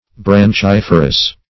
Search Result for " branchiferous" : The Collaborative International Dictionary of English v.0.48: Branchiferous \Bran*chif"er*ous\, a. (Anat.)
branchiferous.mp3